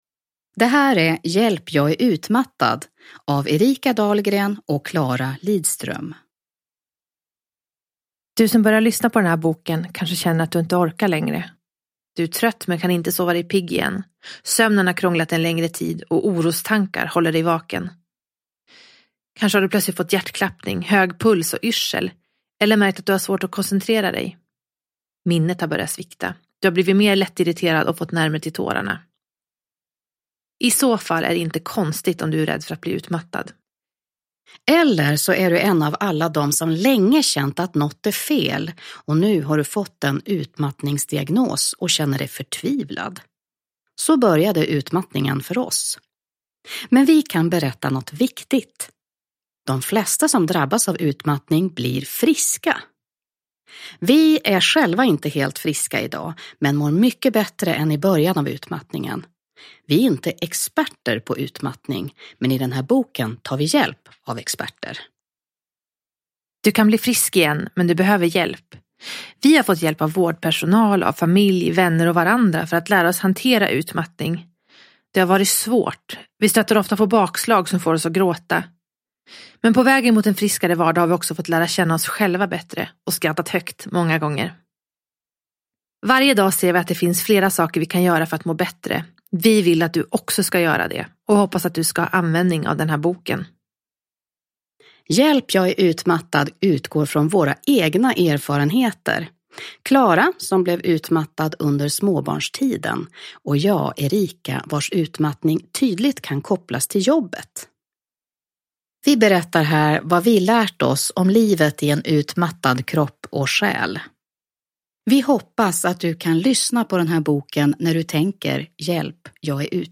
Hjälp jag är utmattad – Ljudbok – Laddas ner